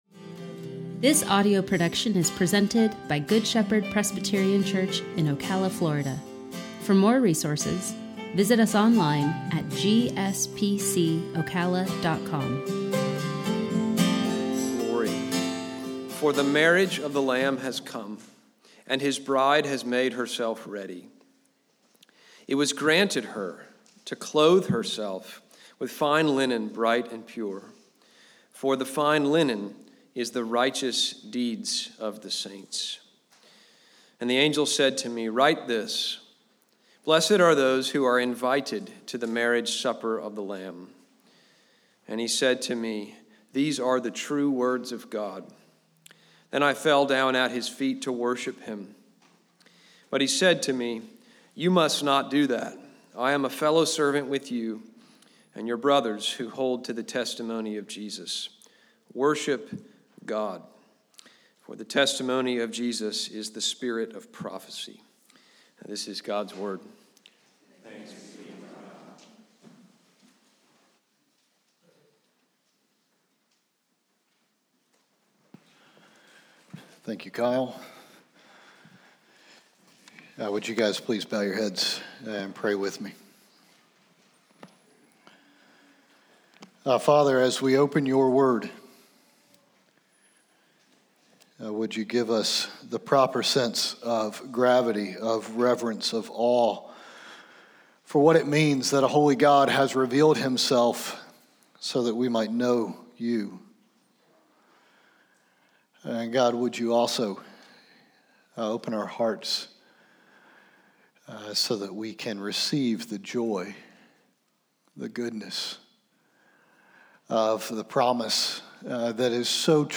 Sermon-Audio-22022.mp3